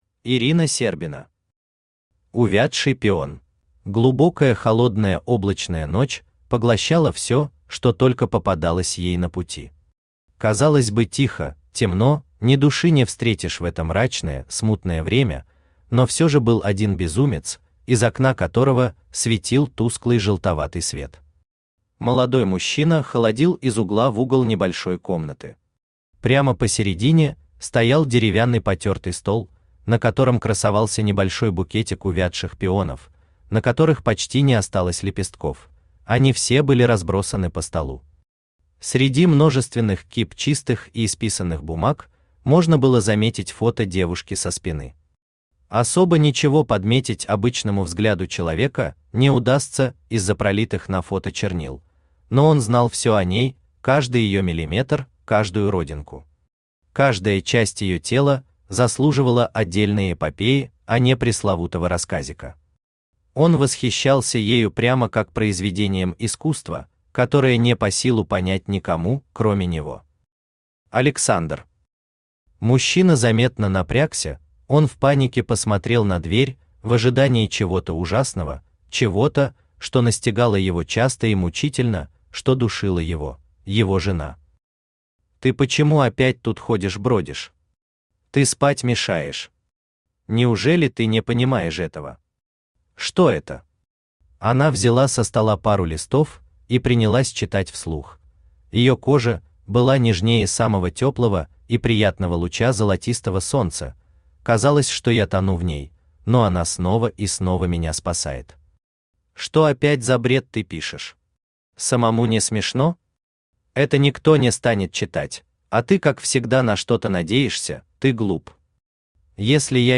Аудиокнига Увядший пион | Библиотека аудиокниг
Aудиокнига Увядший пион Автор Ирина Антоновна Сербина Читает аудиокнигу Авточтец ЛитРес.